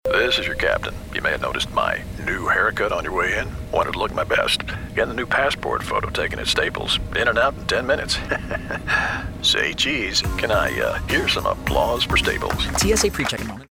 Digital Home Studio
Shure KSM 32 Large diaphragm microphone
Radio Portfolio